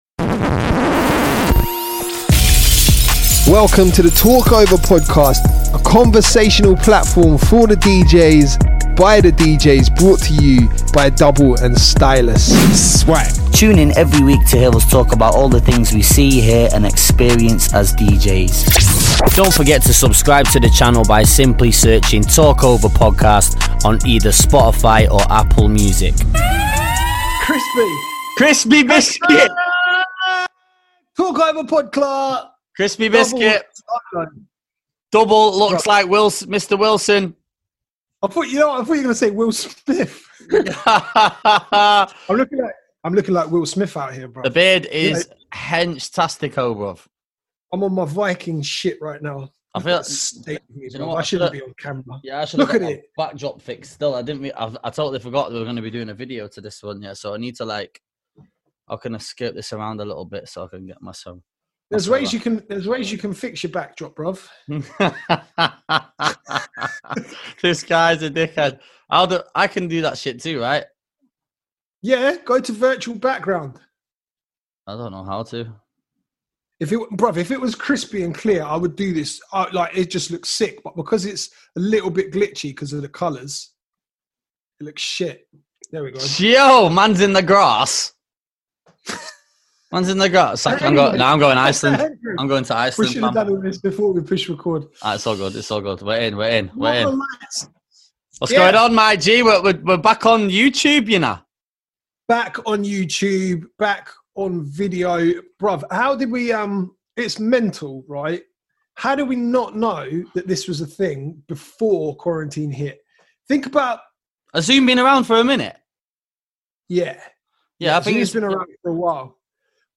We discuss relevant topics, giving our unfiltered & honest opinions about anything that comes up in conversation!